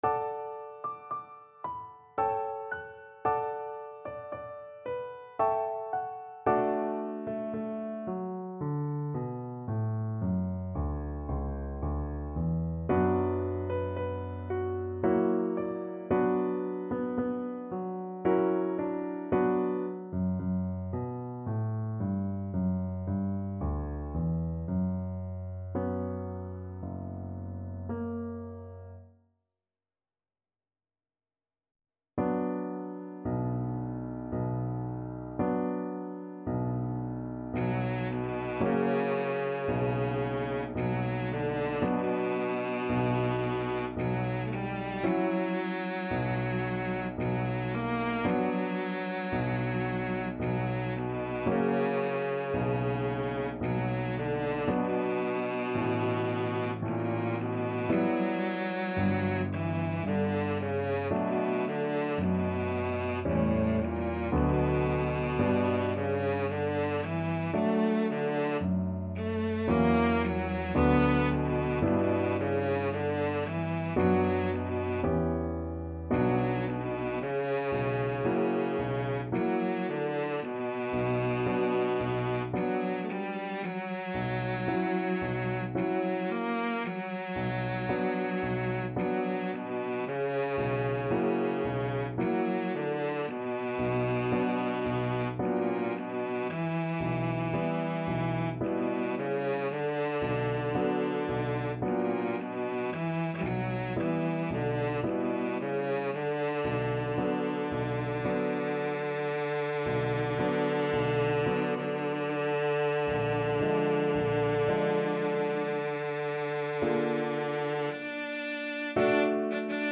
Cello
3/4 (View more 3/4 Music)
A3-B5
D major (Sounding Pitch) (View more D major Music for Cello )
~ = 56 Andante
Classical (View more Classical Cello Music)